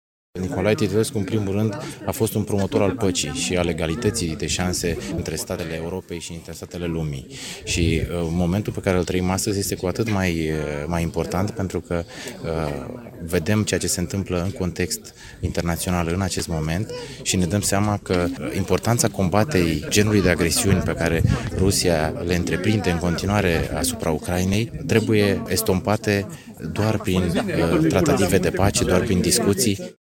Ieri, înainte de începerea cursurilor, studenți și autorități și-au dat întâlnire la mormântul marelui diplomat, de la Biserica Sfântul Nicolae. Secretarul de stat în Ministerul Afacerilor Externe, Andrei Novac: